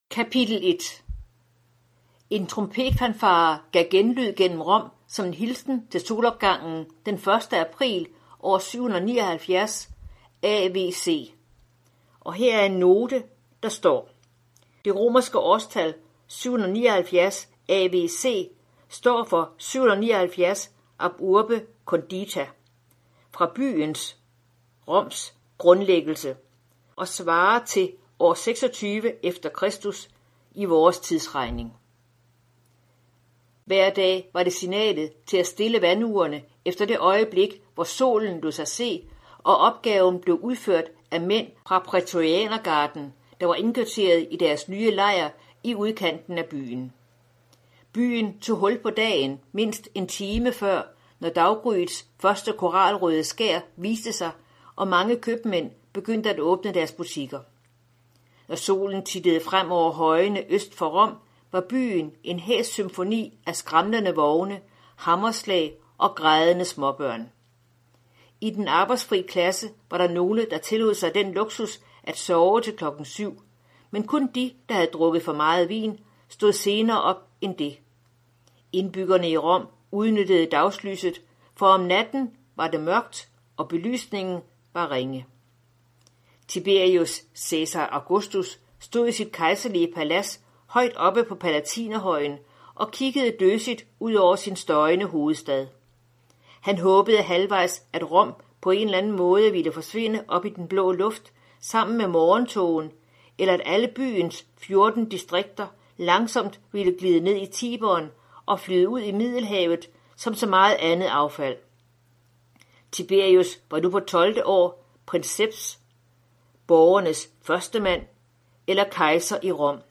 Hør et uddrag af Pontius Pilatus Pontius Pilatus Format MP3 Forfatter Paul L. Maier Bog Lydbog E-bog 99,95 kr.